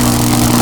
tesla_fire.ogg